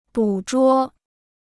捕捉 (bǔ zhuō) Dictionnaire chinois gratuit